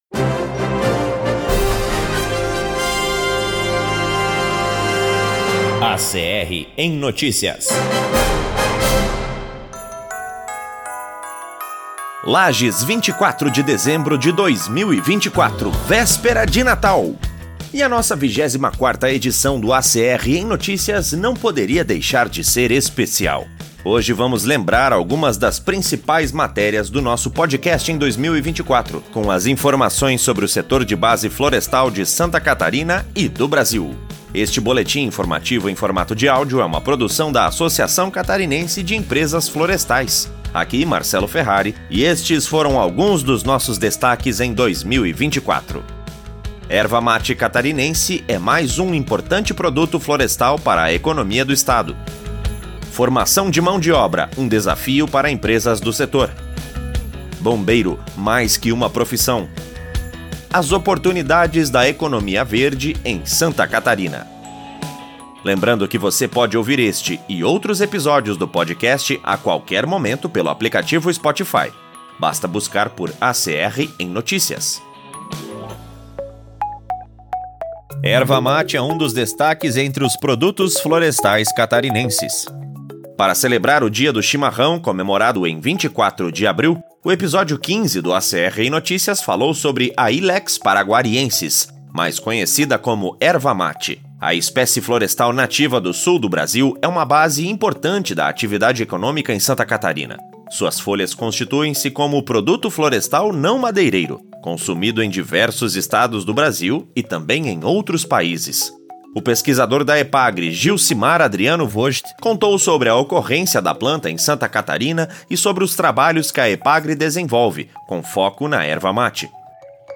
Hoje vamos lembrar algumas das principais matérias do nosso podcast em 2024, com as informações sobre o setor de base florestal de Santa Catarina e do Brasil. O boletim informativo em formato de áudio é uma produção da Associação Catarinense de Empresas Florestais.